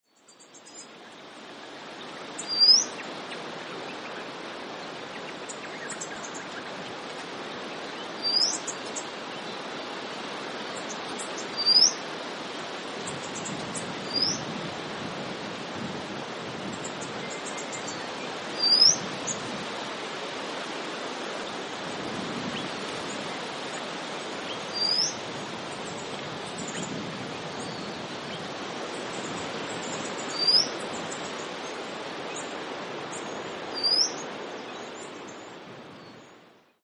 Golden Whistler - Pachycephala pectoralis
Contact call is a rising 'seep'.
Call 2: contact calls: Striated Thornbills chatter as well
Golden_Whistler_contact.mp3